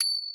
Key-rythm_ching_01.wav